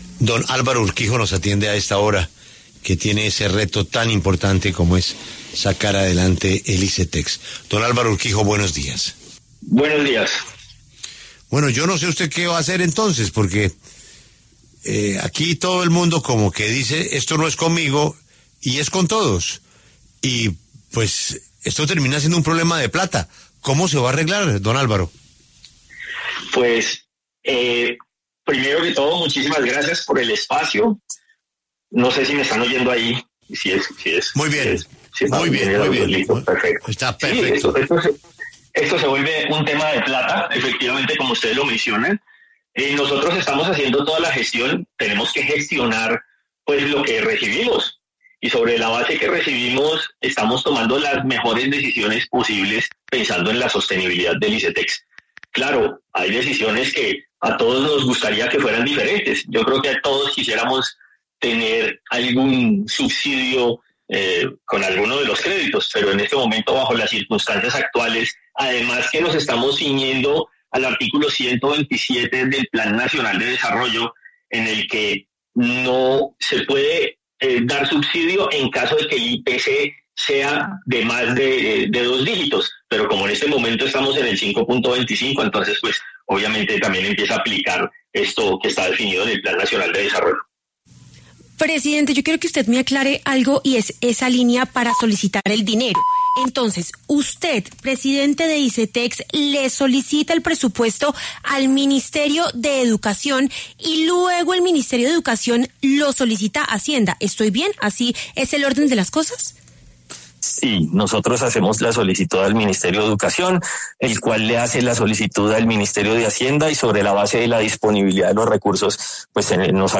El presidente del Icetex, Alvaro Urquijo, habló en La W sobre la eliminación de subsidios por parte del Gobierno que incrementará las cuotas de más de 300.000 beneficiarios del Icetex.